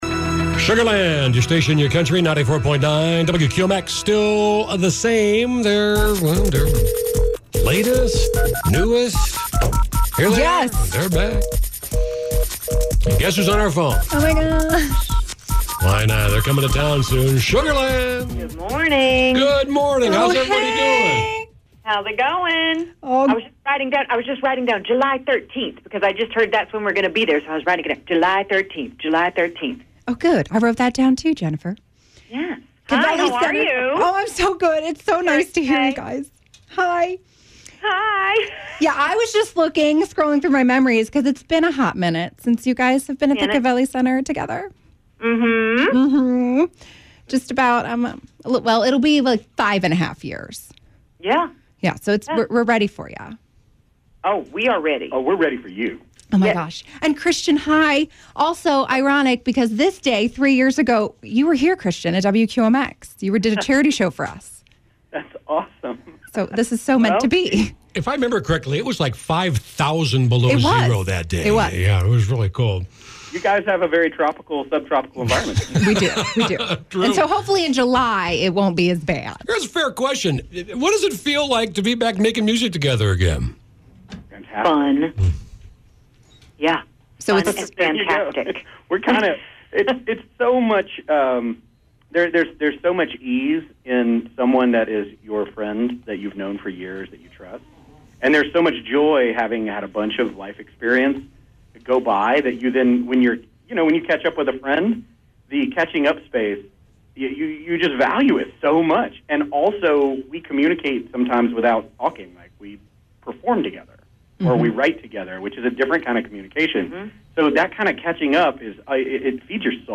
Of course, I had to have oxygen brought in when my Sugarland called in to chat about their new tour and album.
Sugarland-Interview-13118.mp3